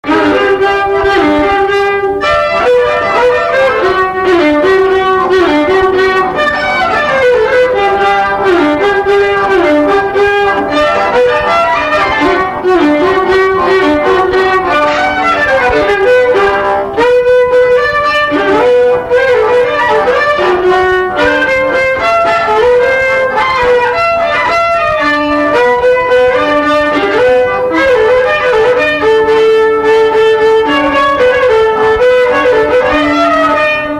Polka
Instrumental
danse : polka
Pièce musicale inédite